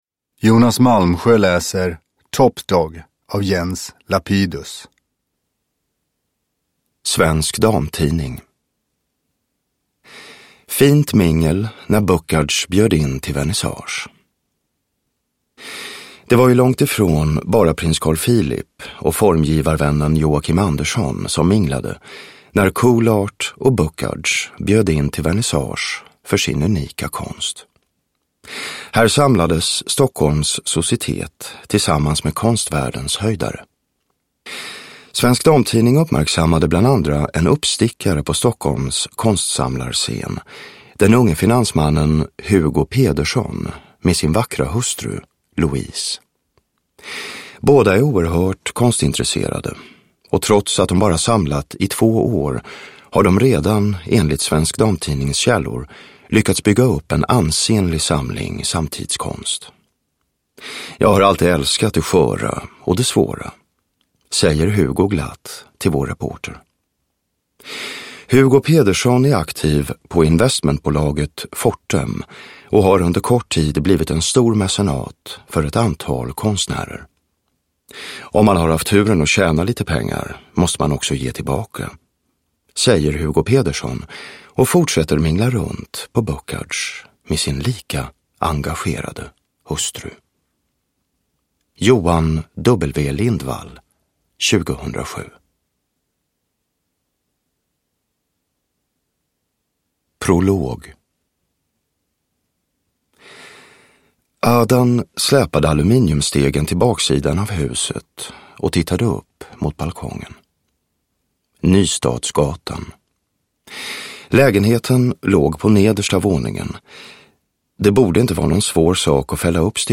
Top dogg – Ljudbok – Laddas ner
Uppläsare: Jonas Malmsjö